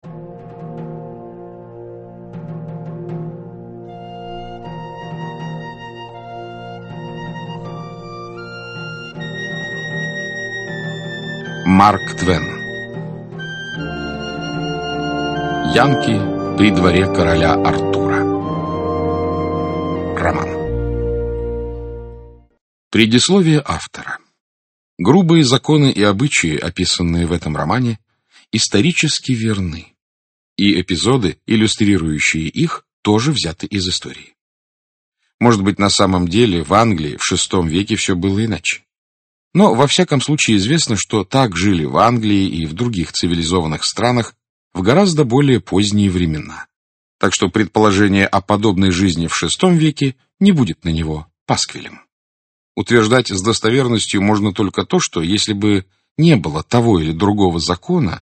Aудиокнига Янки при дворе короля Артура Автор Марк Твен Читает аудиокнигу Александр Клюквин.